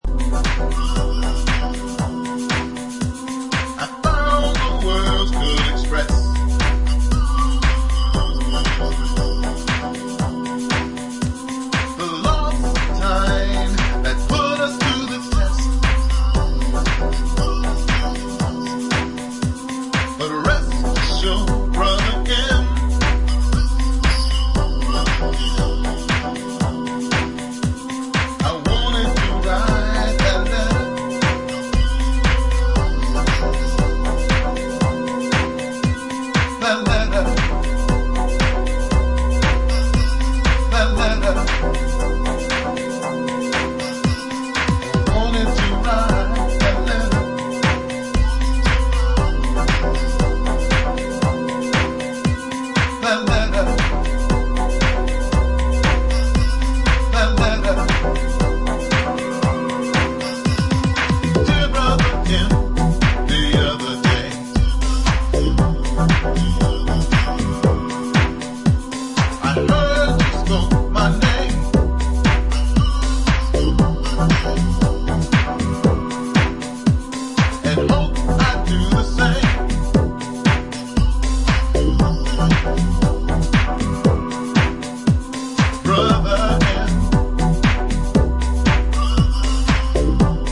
DETROIT